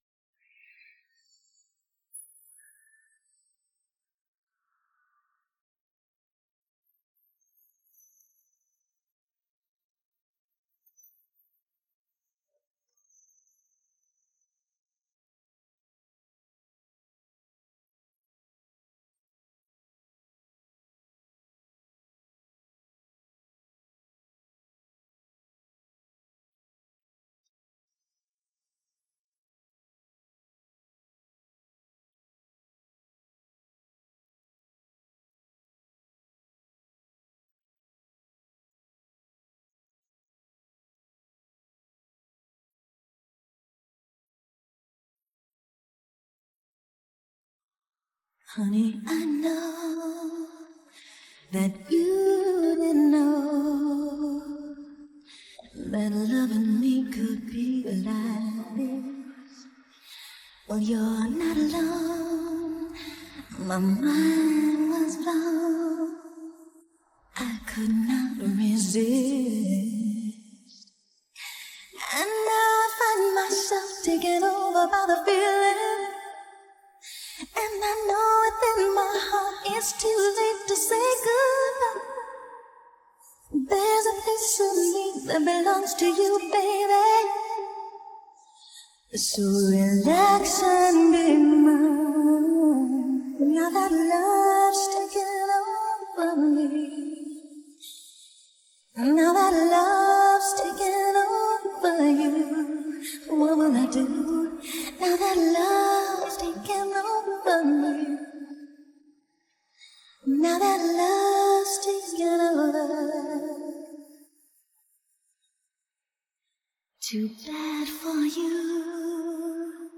I used musical rebalance in izotope 9.